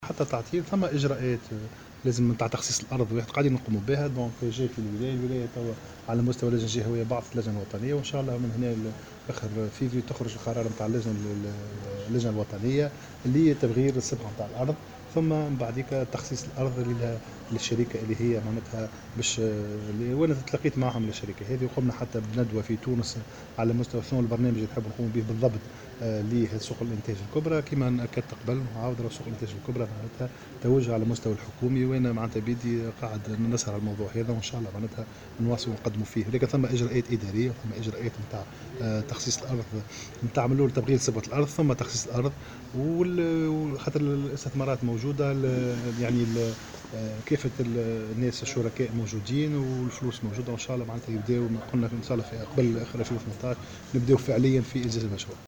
نفى وزير التجارة، عمر الباهي في تصريح اليوم لمراسل "الجوهرة أف أم" على هامش زيارته لولاية سيدي بوزيد وجود أي تعطيلات "مقصودة" بخصوص مشروع إنجاز سوق الانتاج الكبرى بسيدي بوزيد.